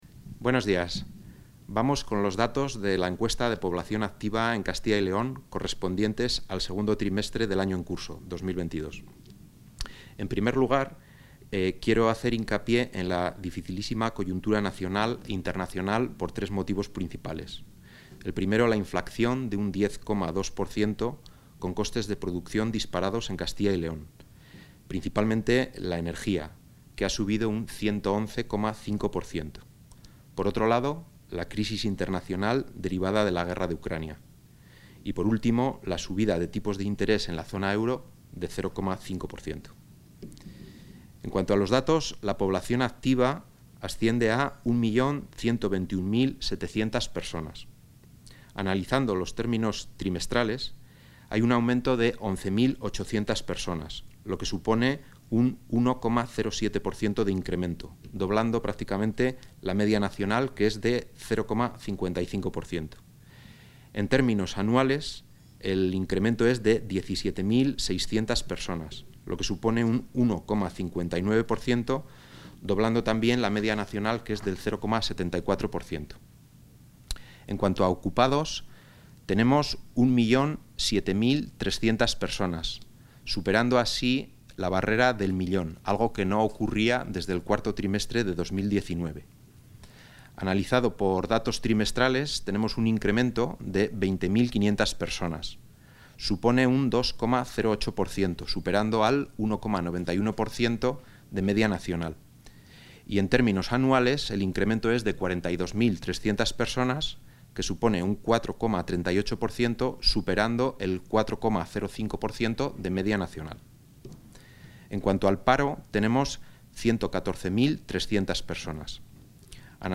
El viceconsejero de Dinamización Industrial y Laboral y Gerente del Servicio Público de Empleo, Óscar Villanueva, ha valorado hoy los datos de la EPA del segundo trimestre del año 2022.